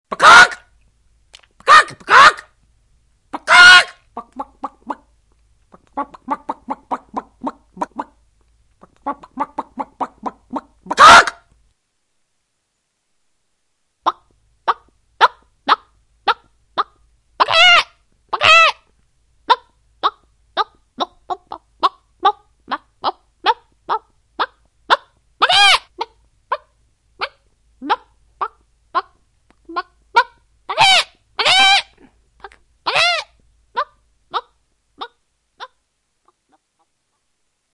Angry Chicken Imitations Sound Button - Free Download & Play
Bird Sounds153 views